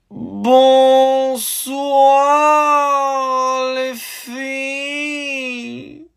Bonsoir lent - Bouton d'effet sonore